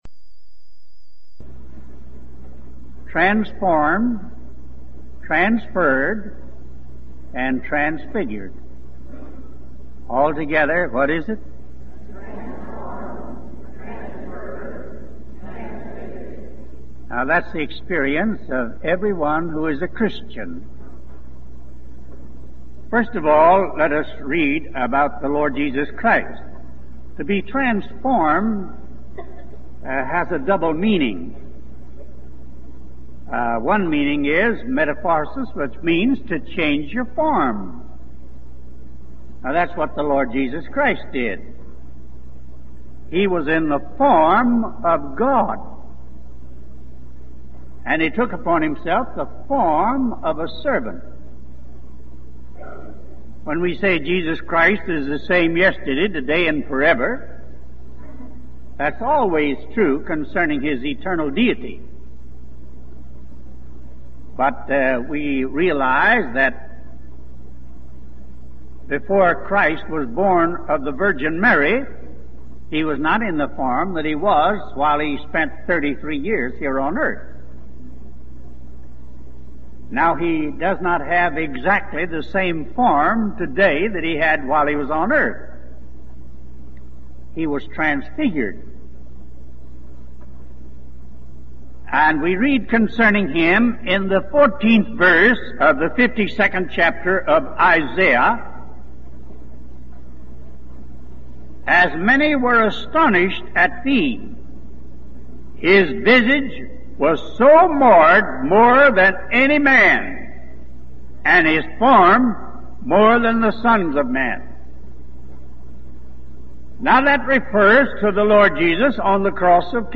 When you're done, explore more sermons .